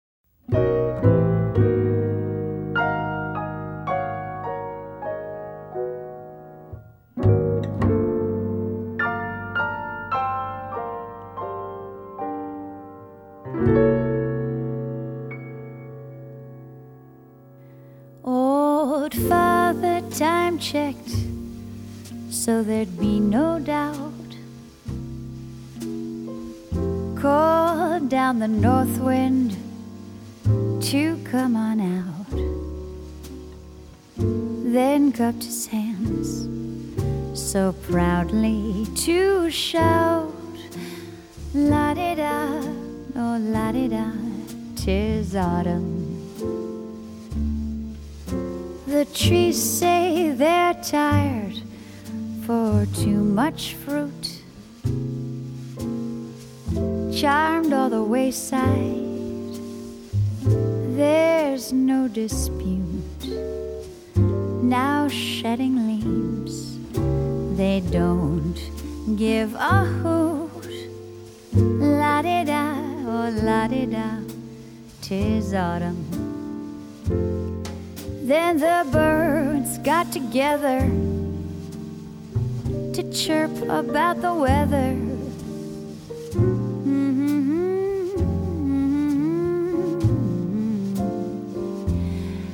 爵士及藍調 (499)